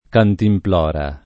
vai all'elenco alfabetico delle voci ingrandisci il carattere 100% rimpicciolisci il carattere stampa invia tramite posta elettronica codividi su Facebook cantimplora [ kantimpl 0 ra ] (pop. cantinflora [ kantinfl 0 ra ]) s. f.